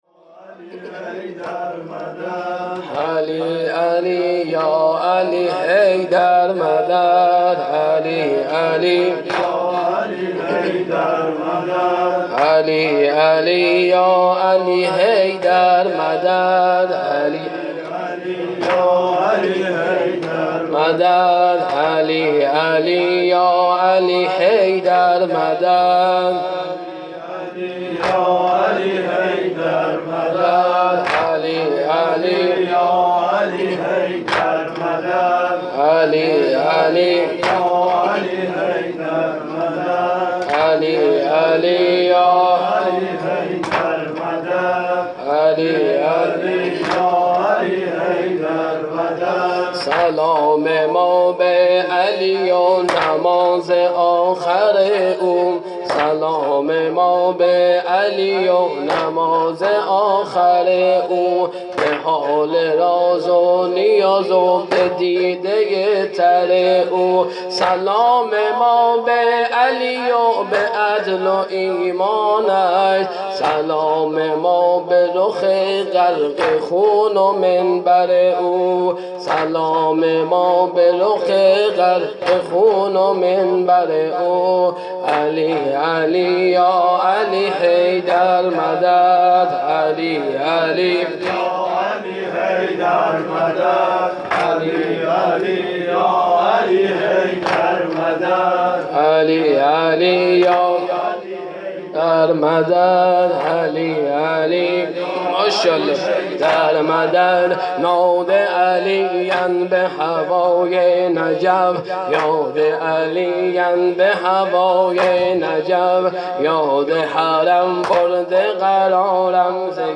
شهادت امام علی{ع}97